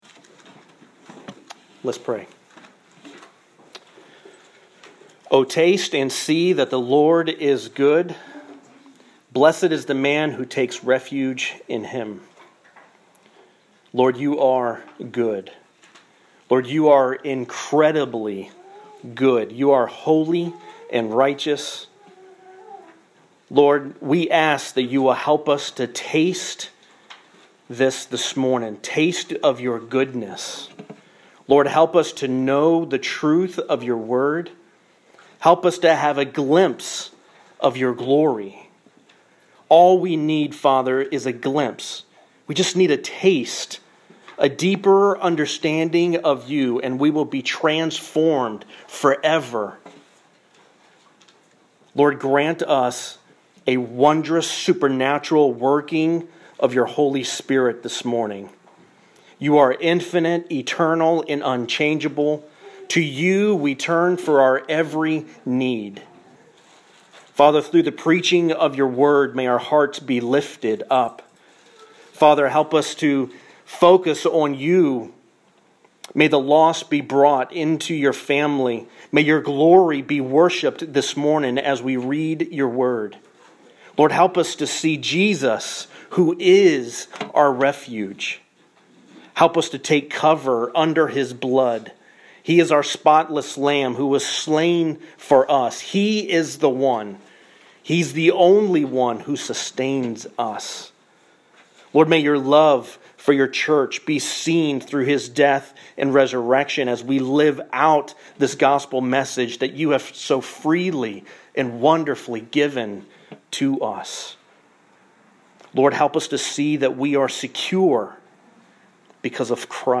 Summary: Commit to the gospel and its effects in your life. Sermon Outline: Guard the deposit given […]